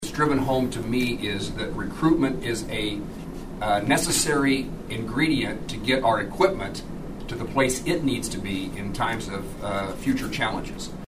Moran spoke about how recruitment has been suffering for the Army, even as Fort Riley sports the highest soldier retention rate.